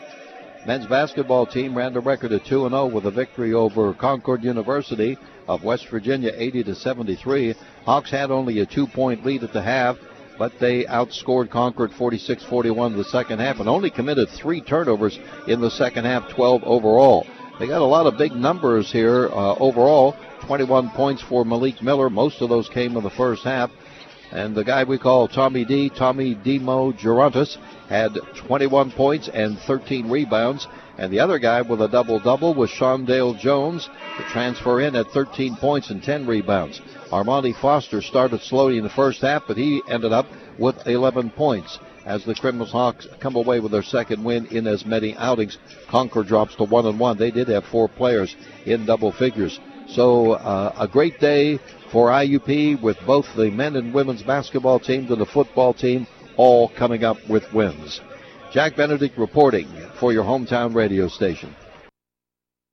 Concord-at-IUP-Recap-2.mp3